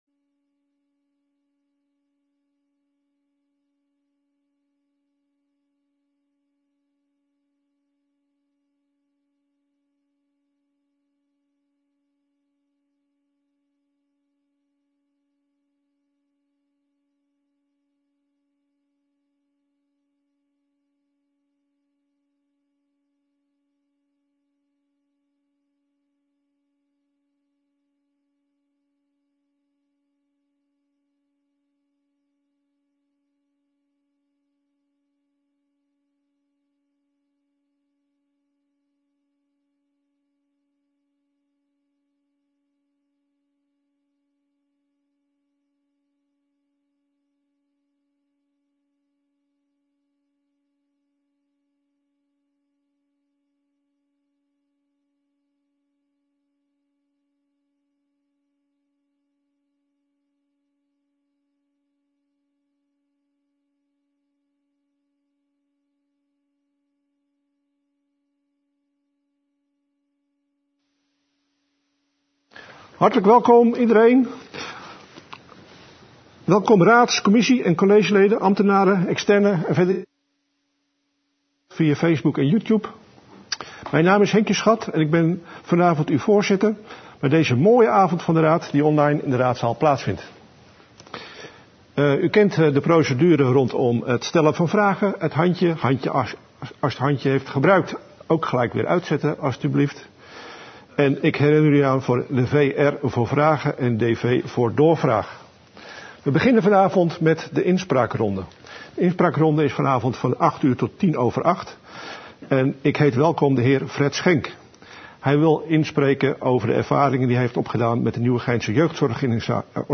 Agenda Nieuwegein - Avond van de Raad Vanuit de Raadzaal donderdag 4 februari 2021 20:00 - 23:00 - iBabs Publieksportaal
Digitale vergadering